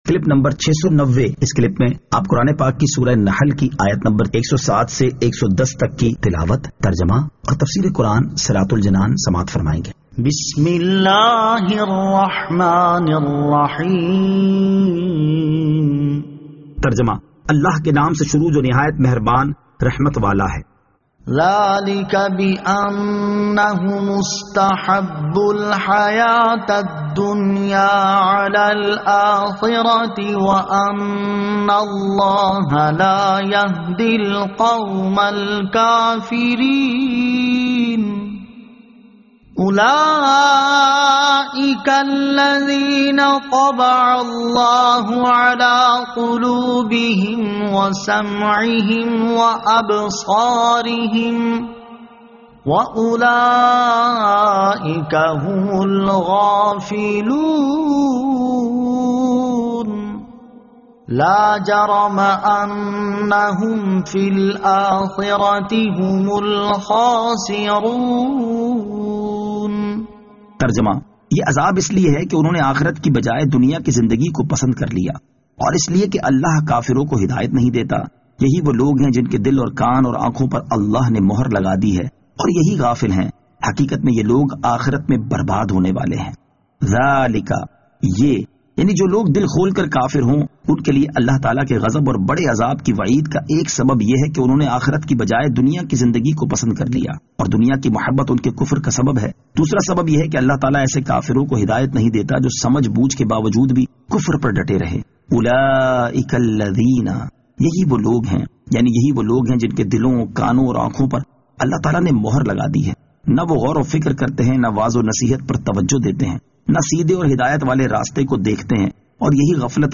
Surah An-Nahl Ayat 107 To 110 Tilawat , Tarjama , Tafseer